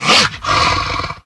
pdog_attack_0.ogg